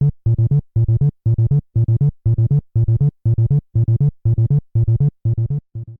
Converted to ogg and added a fade-out.